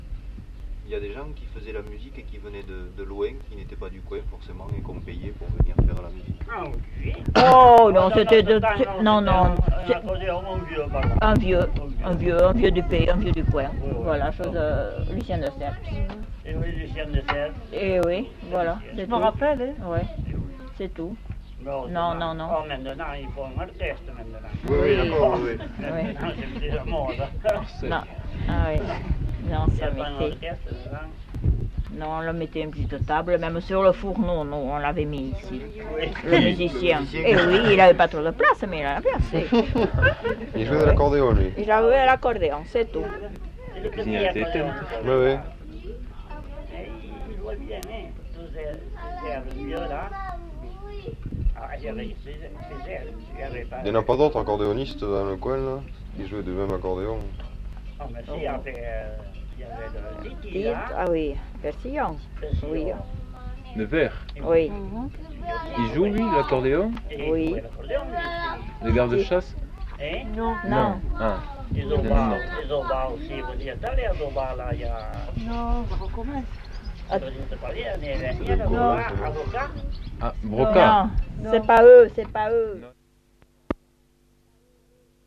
Lieu : Labrit
Genre : témoignage thématique